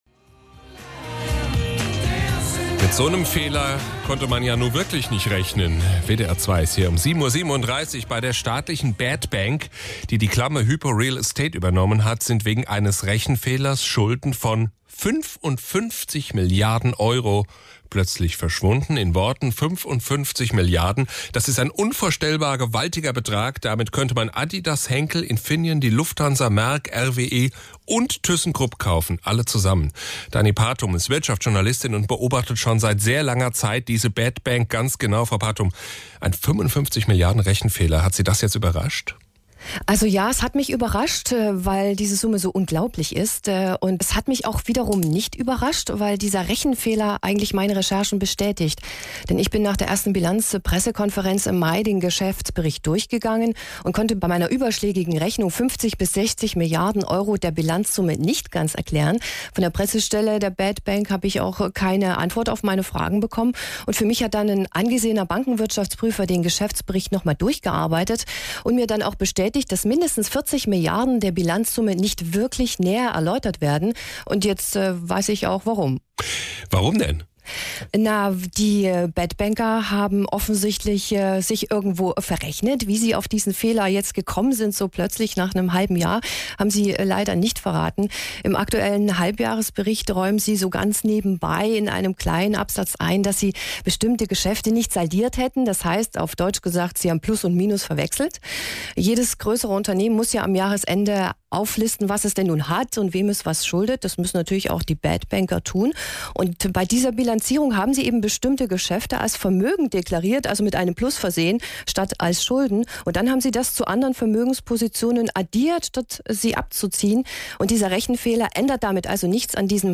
WDR2 — Interview zum 55-Milliarden-Rechenfehler der FMS Wertmanagement